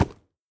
horse_wood4.ogg